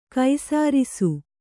♪ kai sārisu